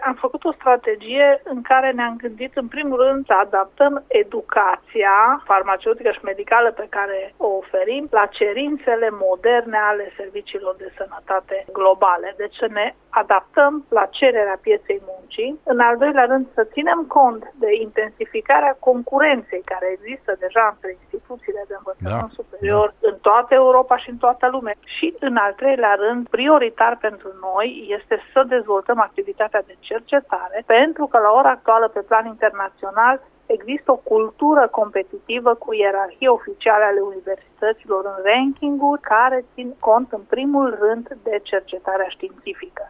Invitat astăzi la Radio Cluj